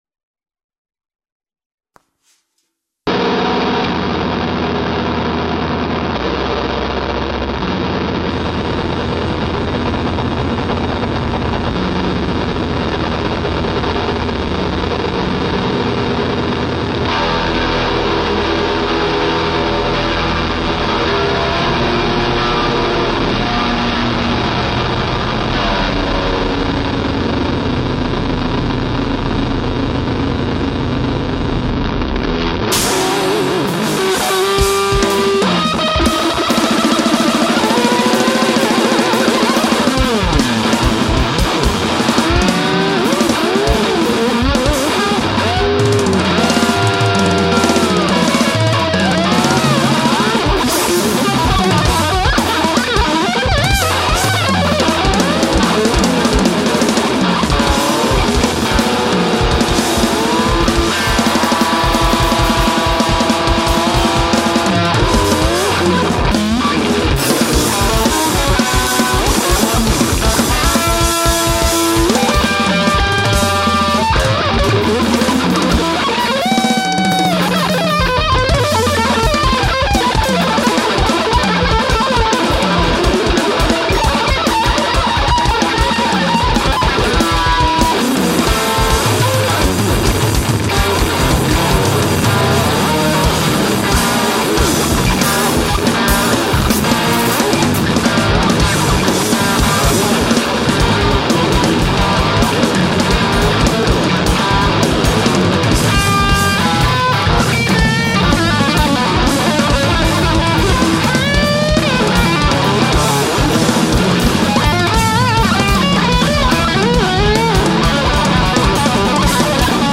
Schlagzeug
Gitarre, Bass